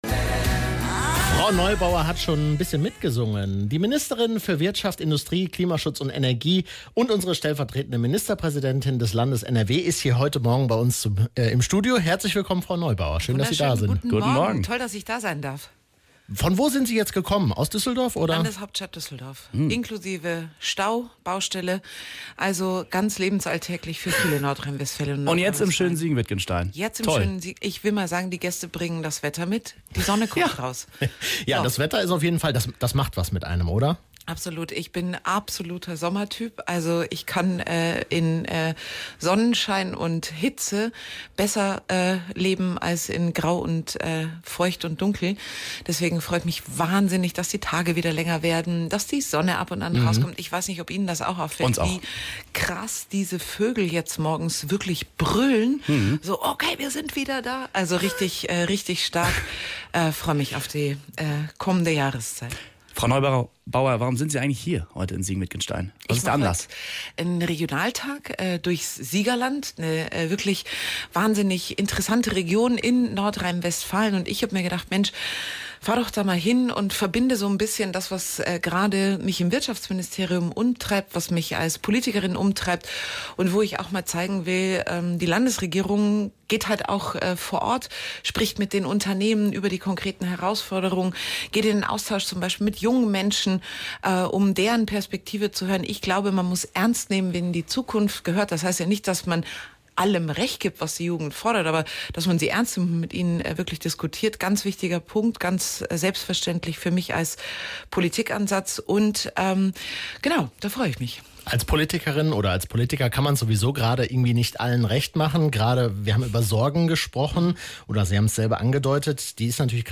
Veröffentlicht: Donnerstag, 26.02.2026 11:05 Anzeige play_circle play_circle Radio Siegen Interview mit Wirtschaftsministerin Mona Neubaur download play_circle Abspielen download Anzeige
interview-mona-neubaur.mp3